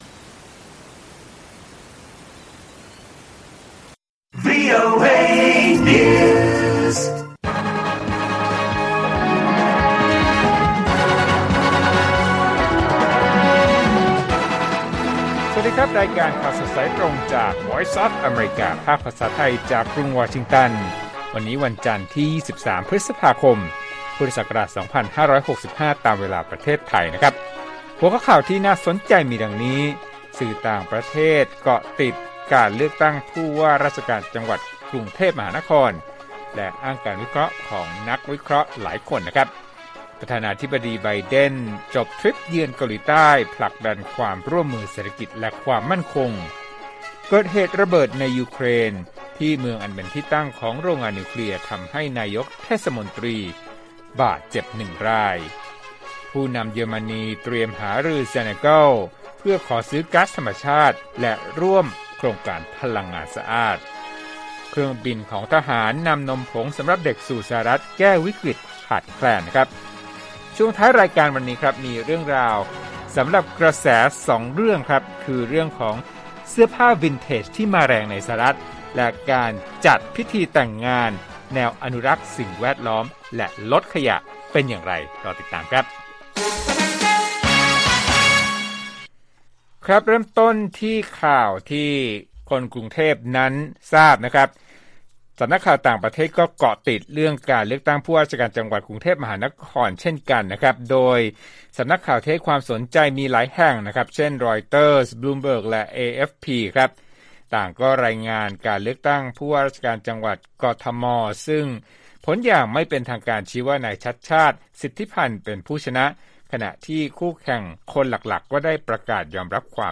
ข่าวสดสายตรงจากวีโอเอ ภาคภาษาไทย 8:30–9:00 น. 23 พฤษภาคม 2565